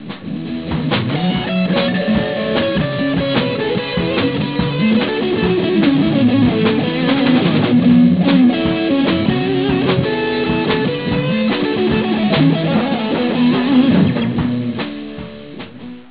These riffs were recorded in mono to minimize file size.
A brief solo from a recent jam (251 KB Wav file).